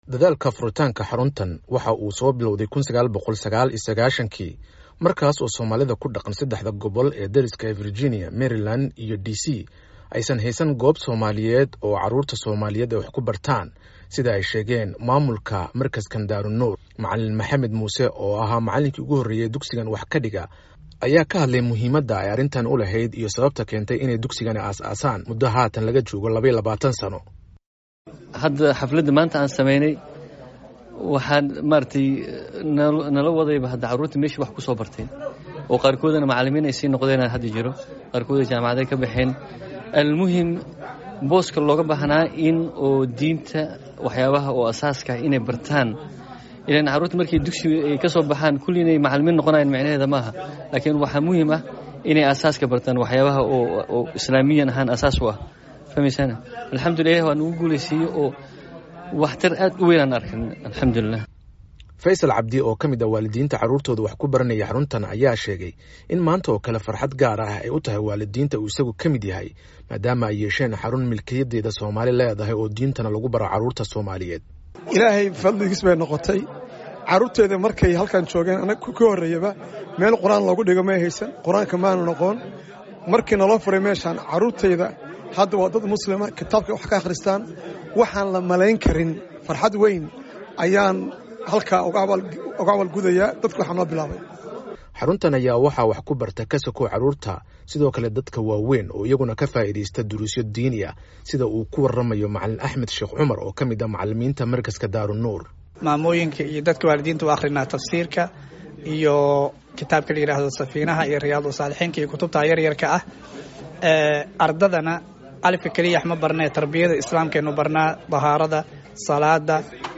Dar al Nur